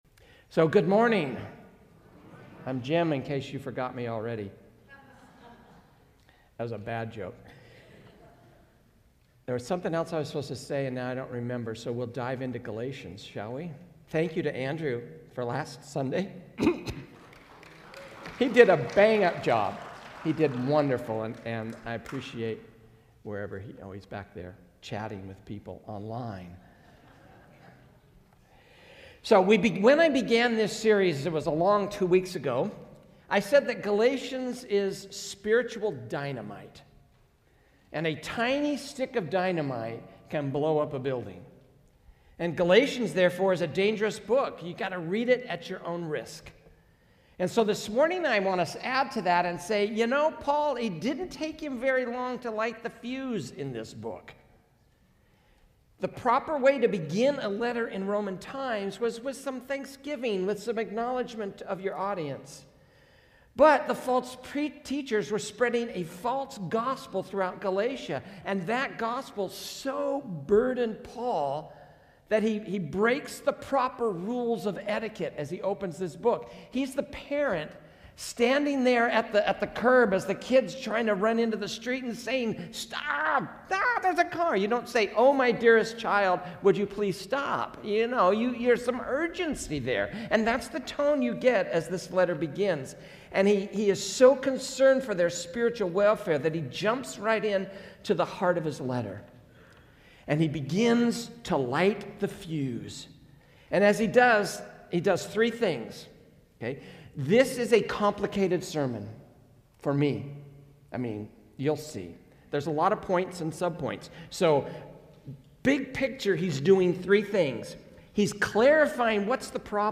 A message from the series "Galatians."